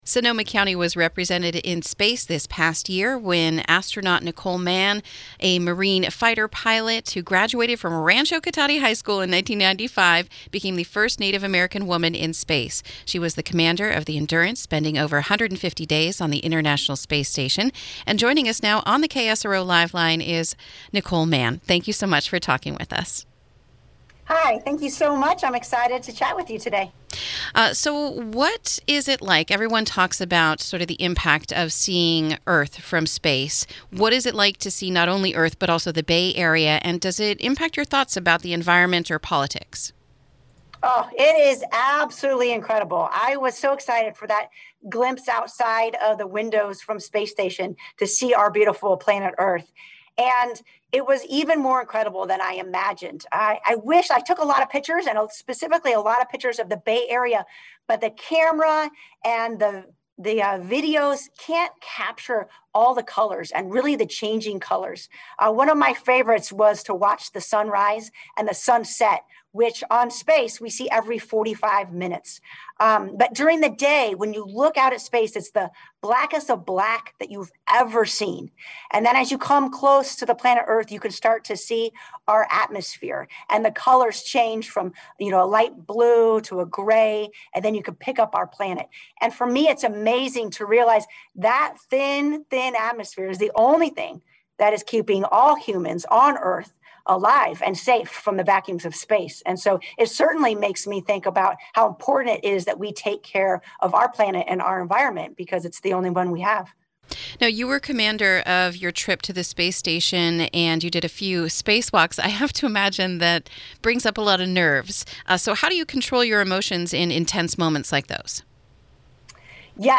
Interview: Astronaut Nicole Mann of Petaluma Speaks About Time in Space